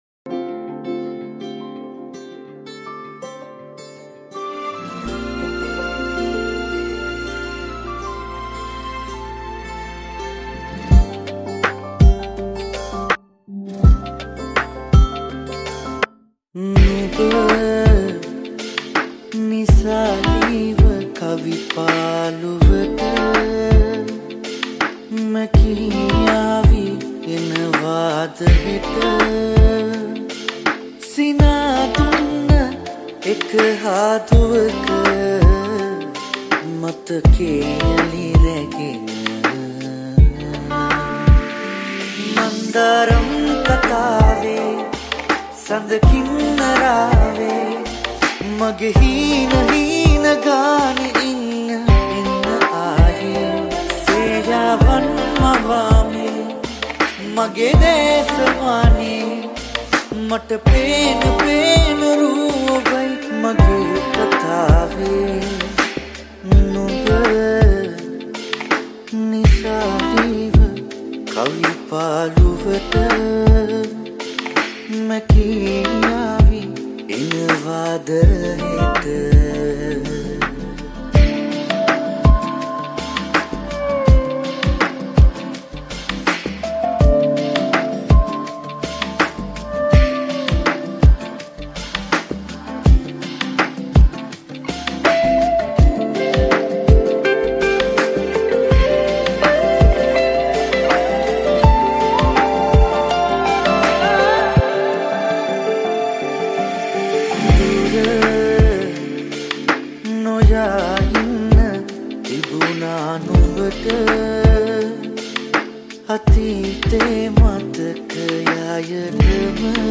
Chillout Remix